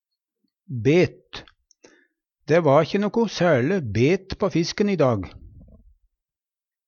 bet - Numedalsmål (en-US)